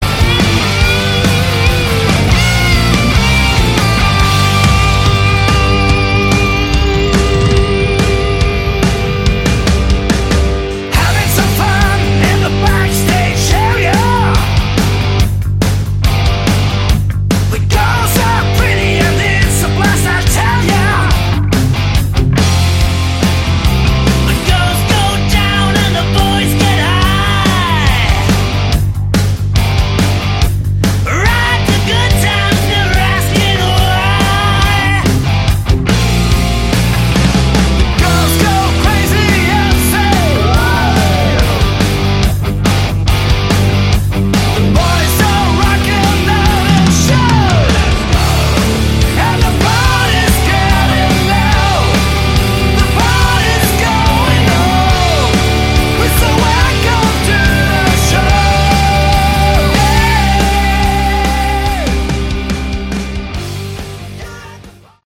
Category: Hard Rock
Vocals, Lead Guitar
Vocals, rhythm guitar
Drums
Vocals, Bass